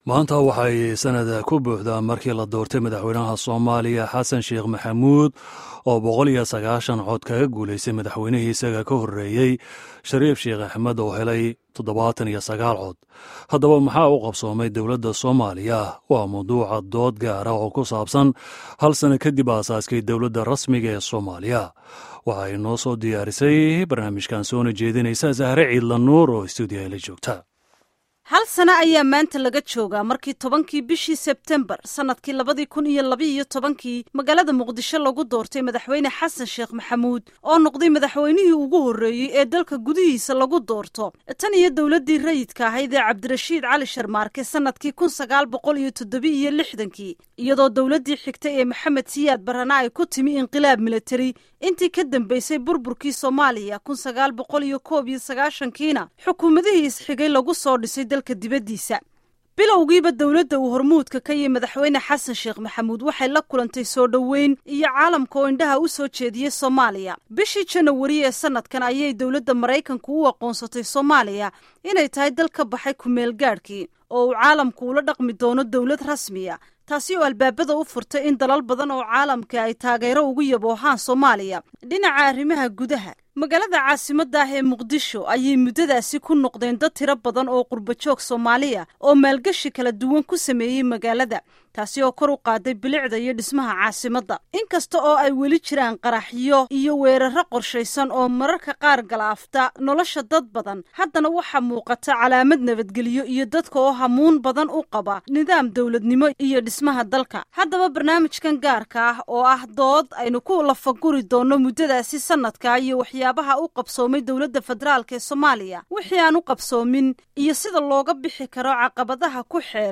Dhageyso dood gaar ah iyo warbixinta Sanad ka dib doorashadii Madaxweyaha